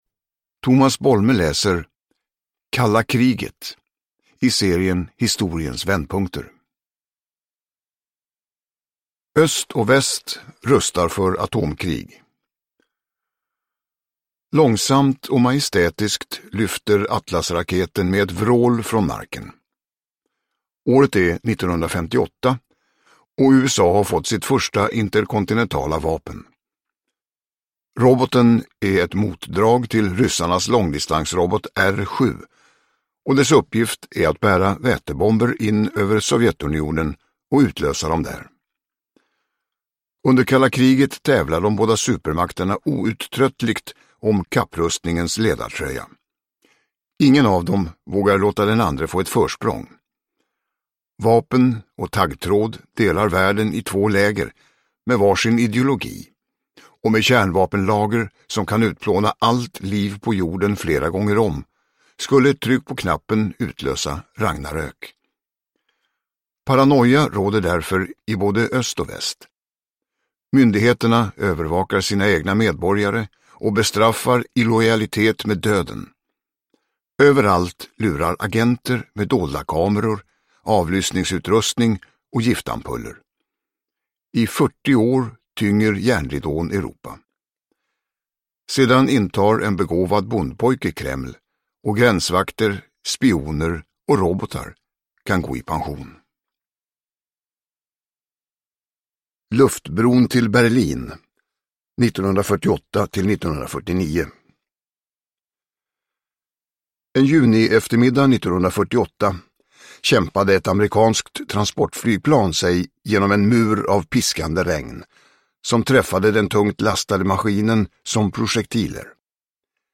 Kalla kriget – Ljudbok – Laddas ner
Uppläsare: Tomas Bolme